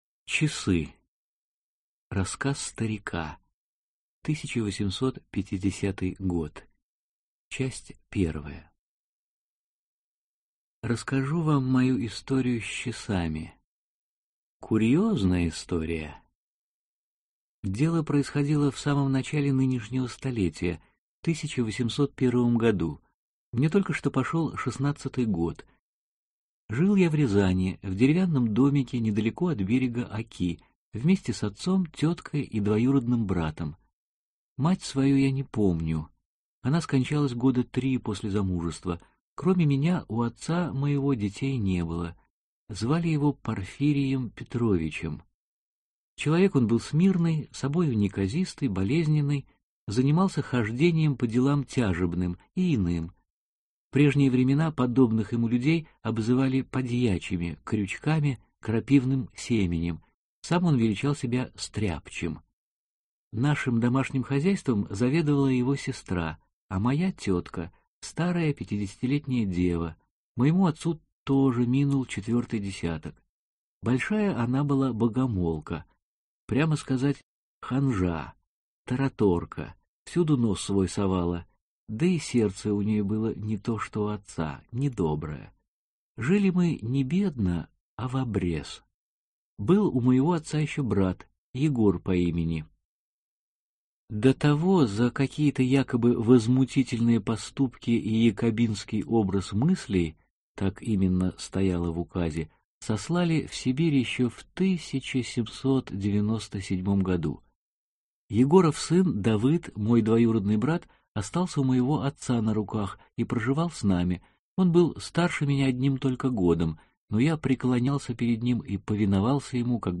Аудиокнига Старые портреты (сборник) | Библиотека аудиокниг